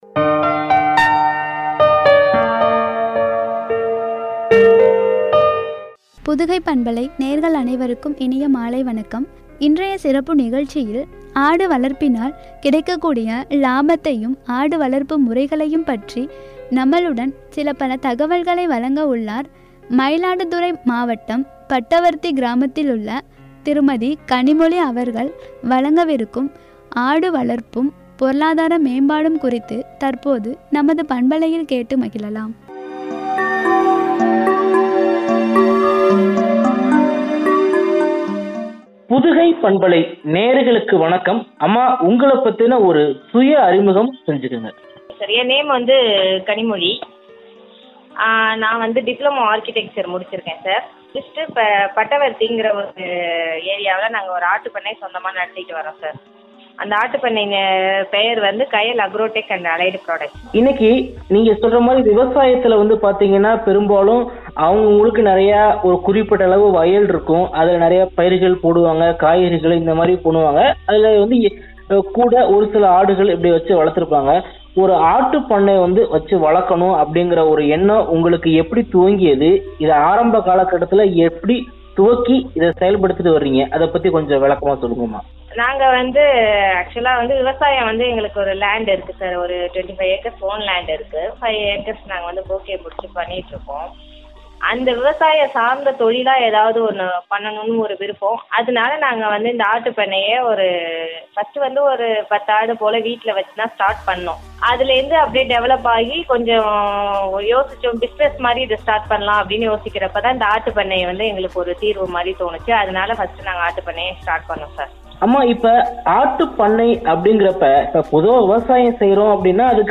பொருளாதார மேம்பாடும்” என்ற தலைப்பில் வழங்கிய உரையாடல்